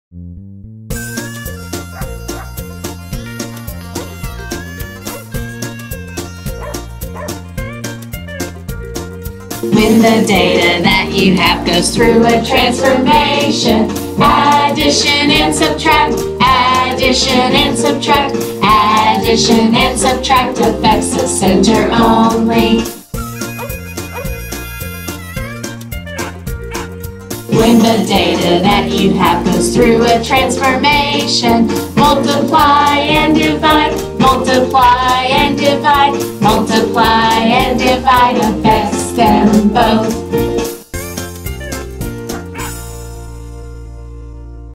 may sing to the tune of "BINGO"